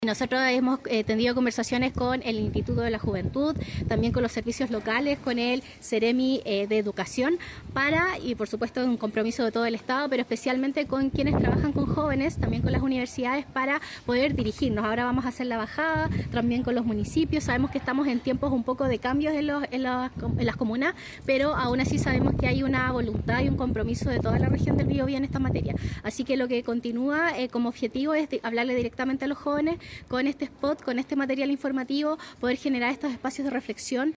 La iniciativa, que fue presentada en el Liceo Polivalente Experimental de Concepción, busca generar consciencia en los jóvenes sobre la violencia de género e informar de los respectivos mecanismos de denuncia.
Además, Contreras comentó el estado en el que se encuentran los objetivos de este programa.